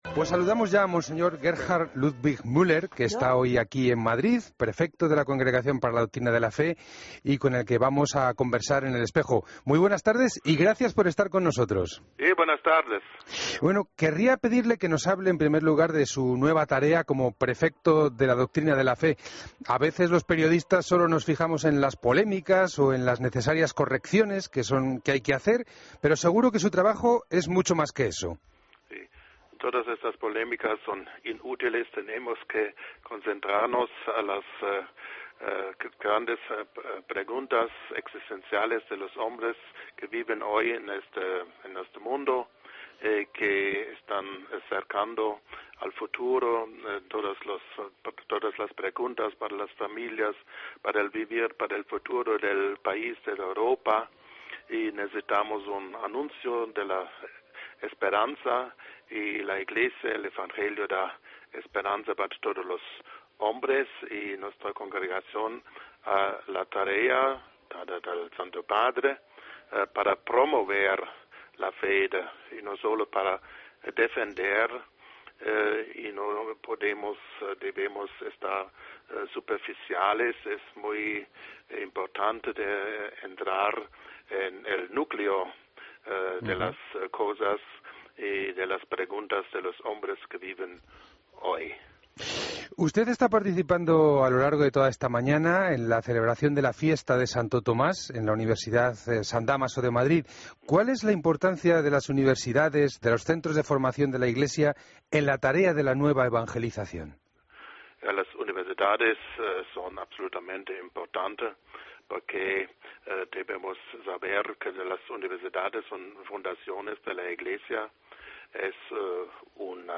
Entrevista a Mons. Gerhard Ludwig Müller, prefecto de la Congregación para la Doctrina de la Fe